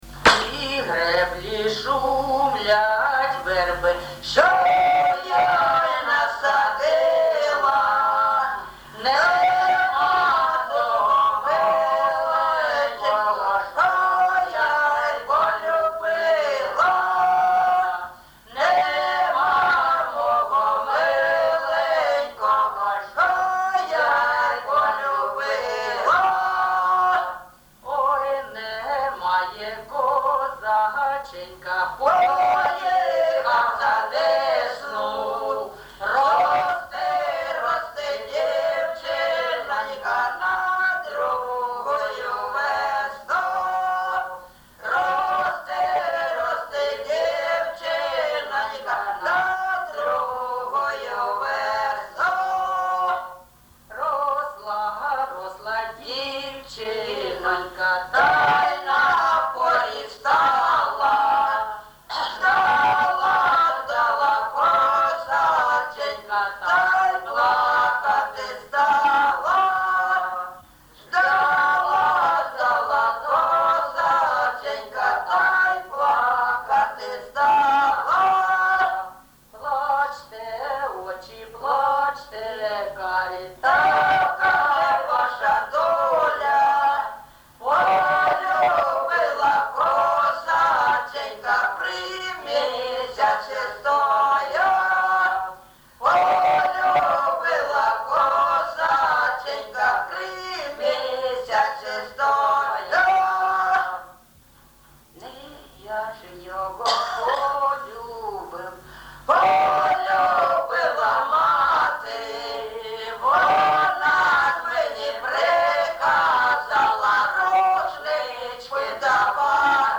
ЖанрПісні з особистого та родинного життя
Місце записум. Єнакієве, Горлівський район, Донецька обл., Україна, Слобожанщина